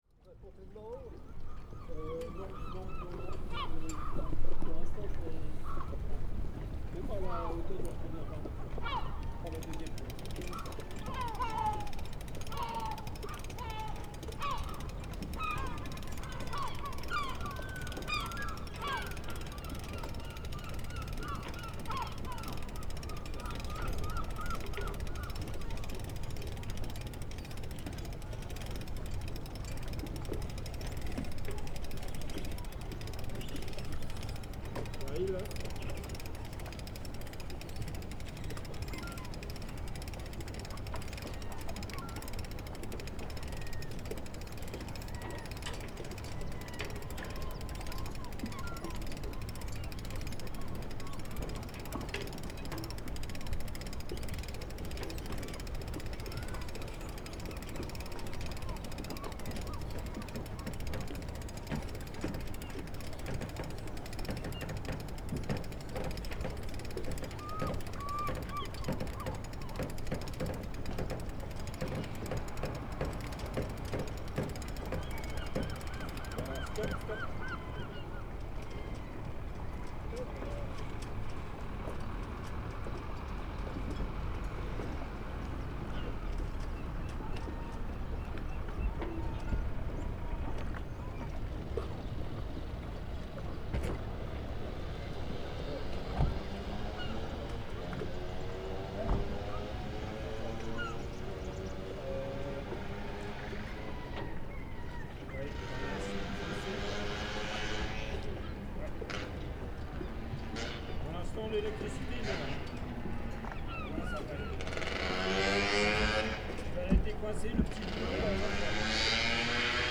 On the Langogen quay, ambiance of the marina. A sailor climbs the mast with equipment (a pot of paint, it seems) with the help of a colleague operating a pulley (rack and pinion).
A moped passing in the background, seagulls… the iconic sounds of Lesconil harbour…